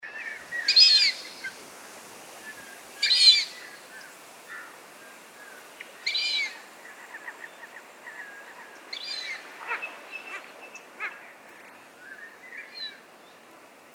The Fulvous Whistling-duck  has a quite different call to that of the familiar whistling call of the Lesser Whistling-duck.  I managed to take some good sound recordings of this call and one is featured below.